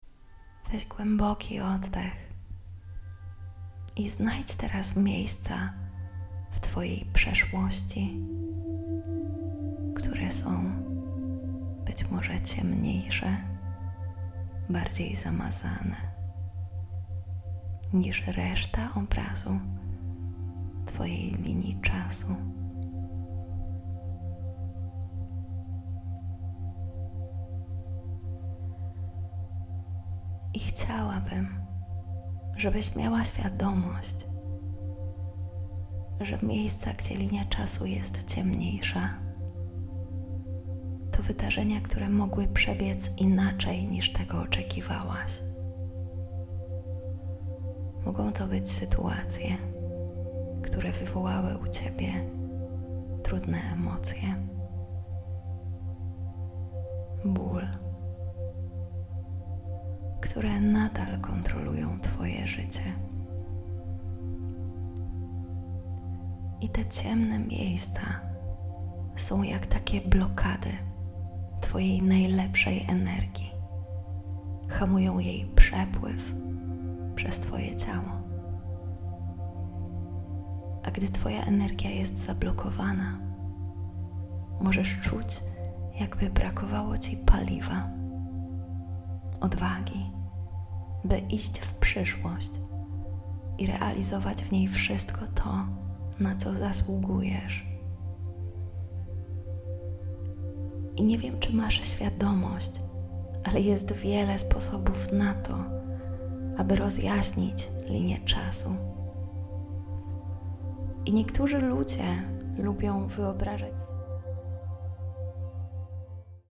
Autohipnoza wprowadzi Cię w stan głębokiego relaksu i zwiększonego skupienia.